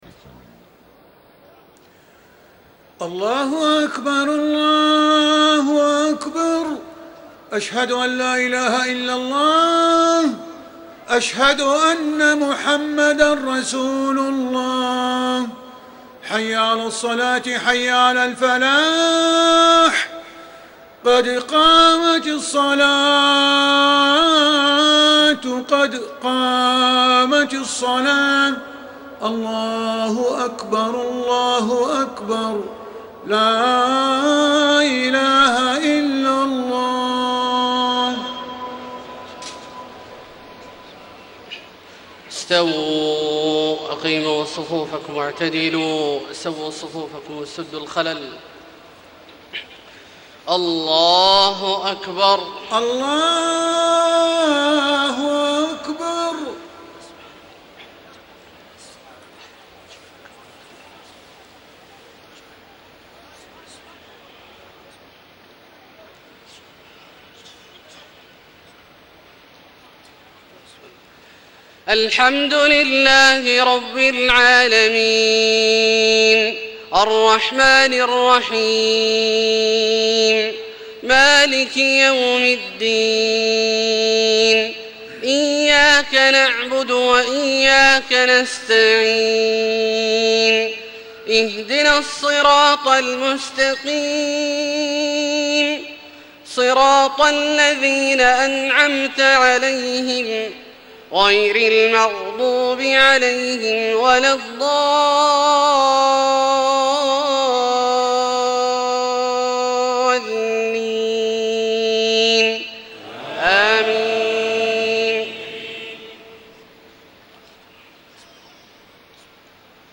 صلاة العشاء 4-8-1434 من سورتي الطارق و الليل > 1434 🕋 > الفروض - تلاوات الحرمين